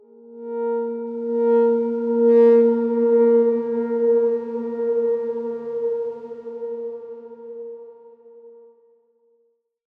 X_Darkswarm-A#3-mf.wav